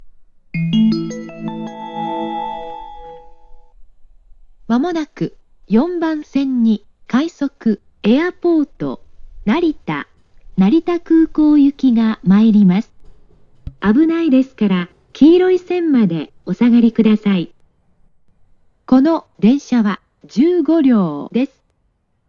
発車メロディー
●音質：良